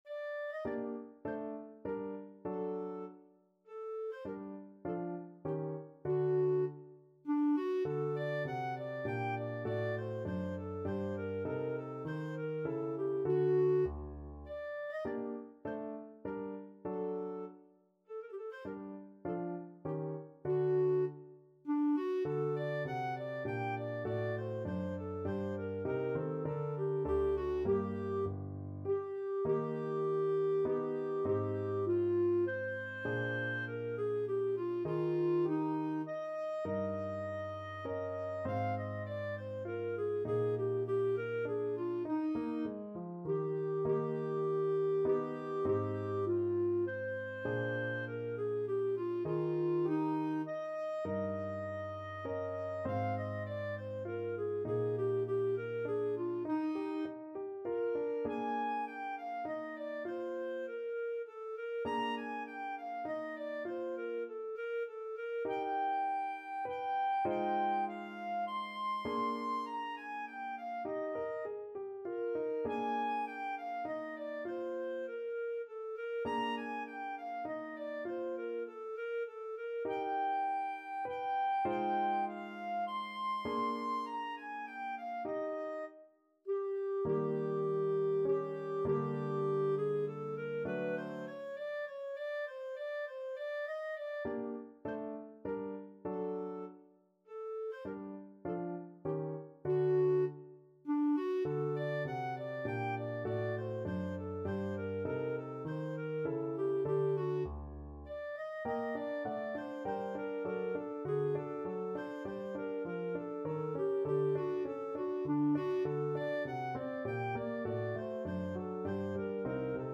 Clarinet
Allegretto
3/4 (View more 3/4 Music)
G minor (Sounding Pitch) A minor (Clarinet in Bb) (View more G minor Music for Clarinet )
Classical (View more Classical Clarinet Music)
beethoven_bagatelles_1_CL.mp3